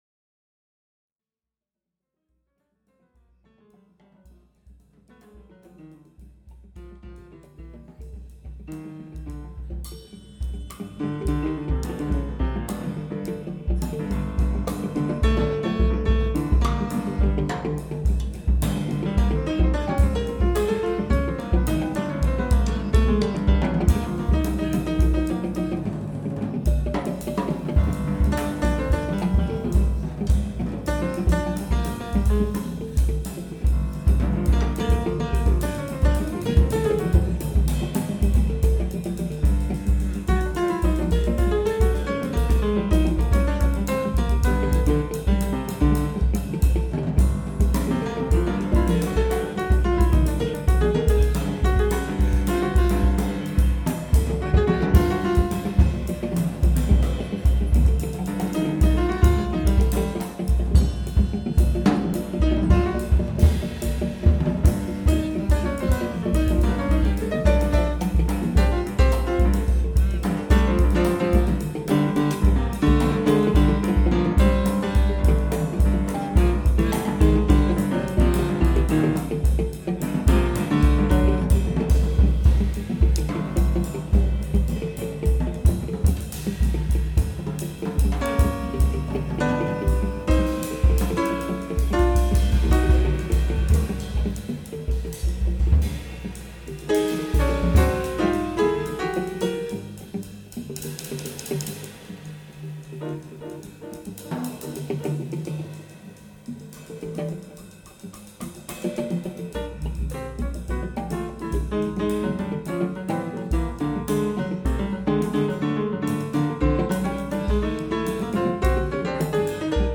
issues du jazz et des musiques improvisées
"free-pop"
batterie